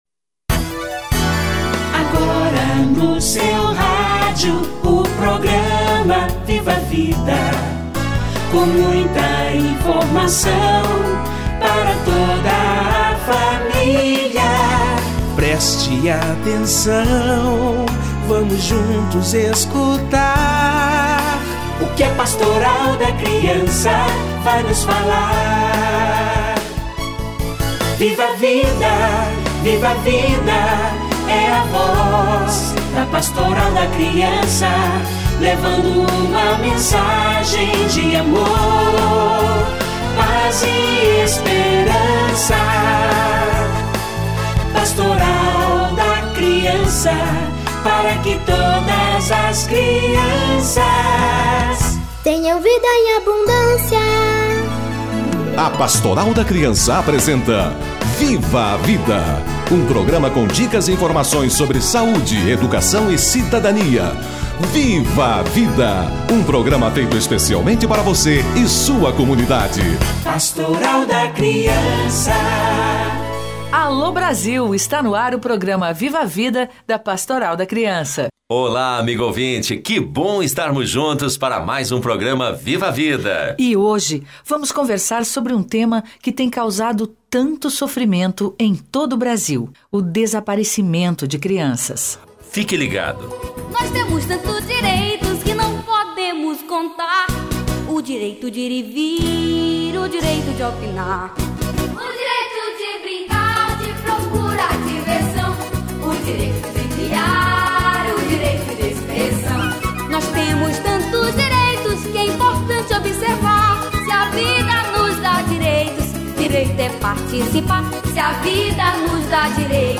Crianças desaparecidas - Entrevista